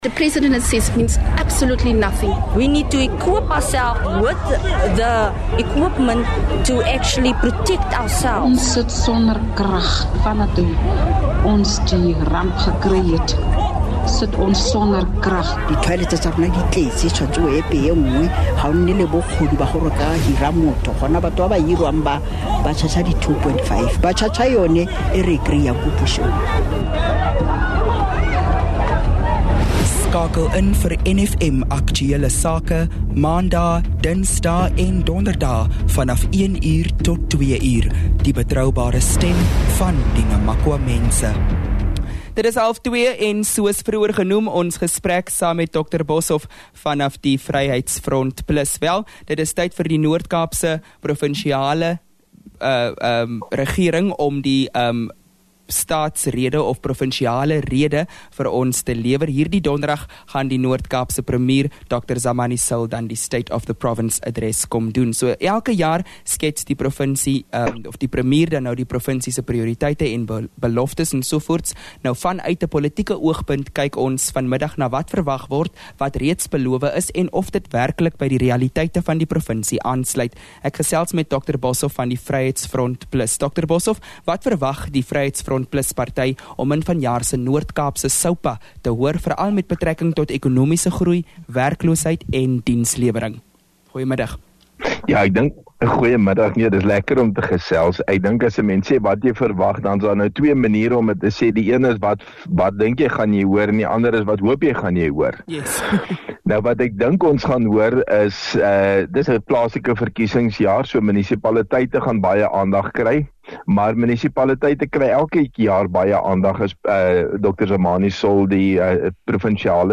In this timely podcast episode, VF+ Provincial Leader in the Northern Cape, Dr Wynand Boshoff, offers a candid analysis of the recently delivered 2026 State of the Province Address (SOPA) by Premier Dr Zamani Saul.